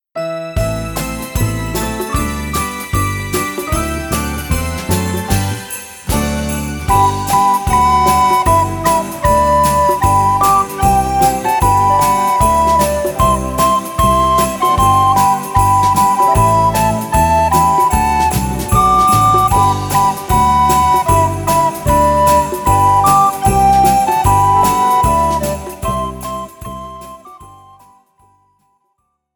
Besetzung: 1-2 Sopranblockflöten